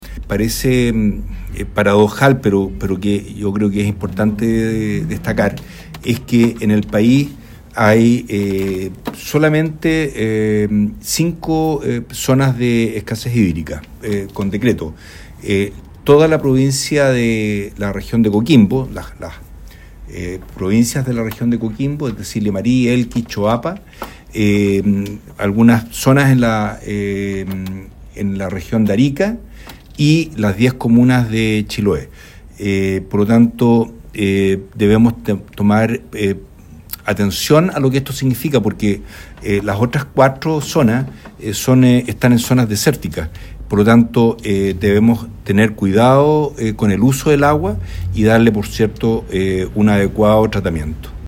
El personero dio cuenta de la situación problemática que arrastra Chiloé desde hace varios años, ya que comparte este decreto con zonas del Norte Chico, como la región de Coquimbo por ejemplo, que son áreas eminentemente desérticas.